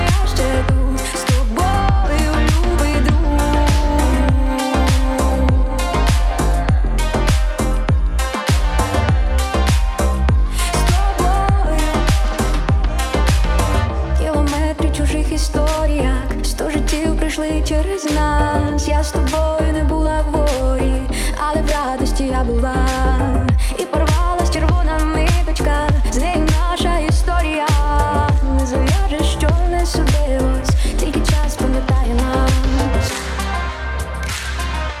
Pop Dance
Жанр: Поп музыка / Танцевальные / Украинские